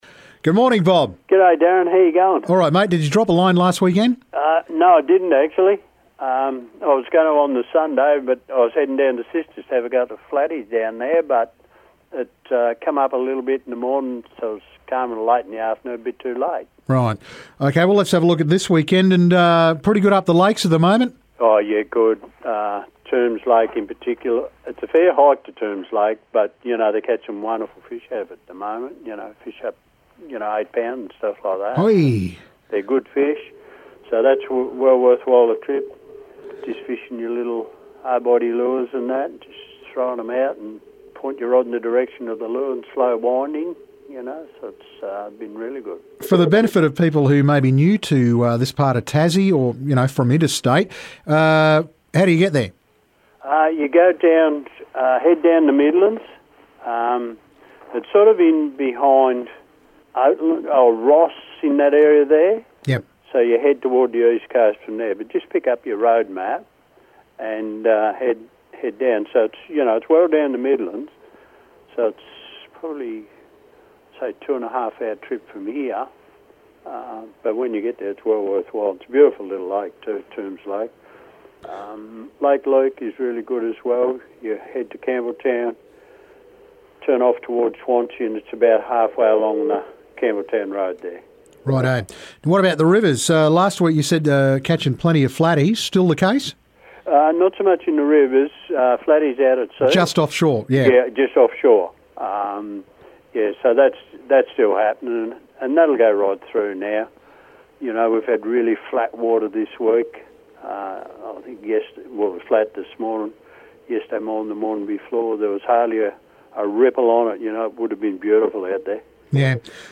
fishing report